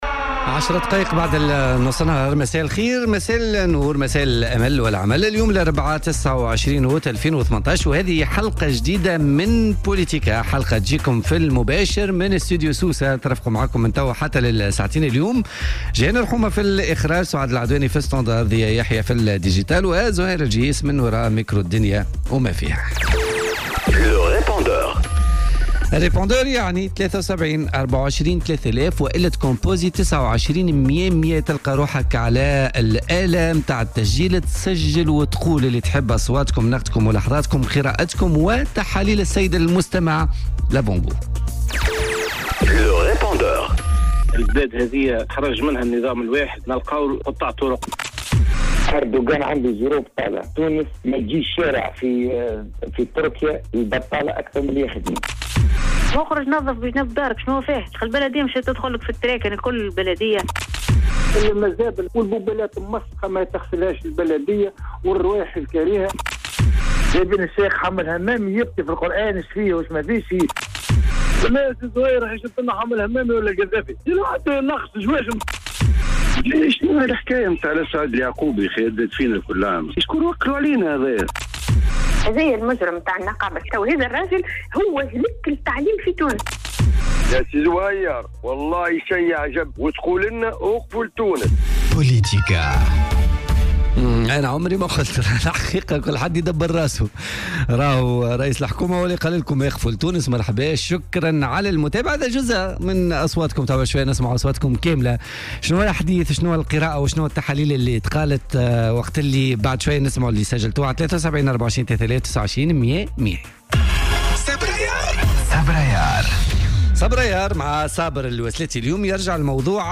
رئيس بلدية المنستير السيد منذر مرزوق ضيف بوليتيكا